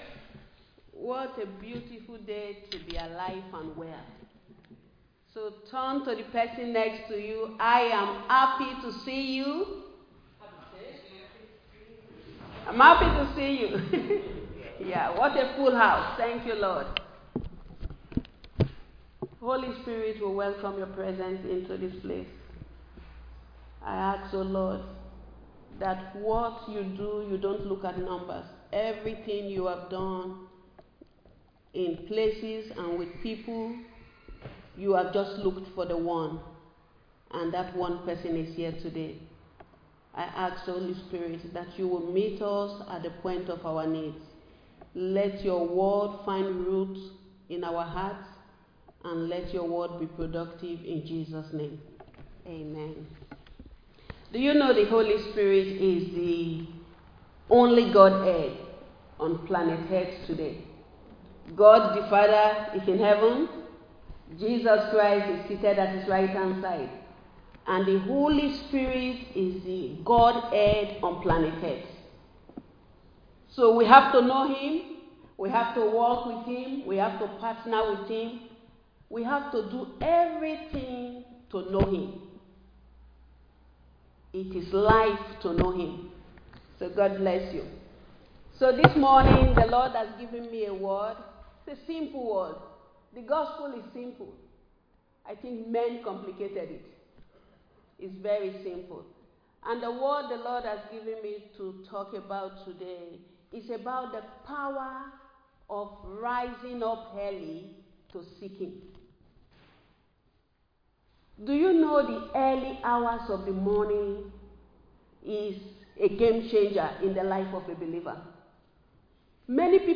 delivers a message on setting a time to meet with God Recorded live in Liberty Church on 13 July 2025